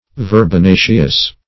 Search Result for " verbenaceous" : The Collaborative International Dictionary of English v.0.48: Verbenaceous \Ver`be*na"ceous\, a. (Bot.)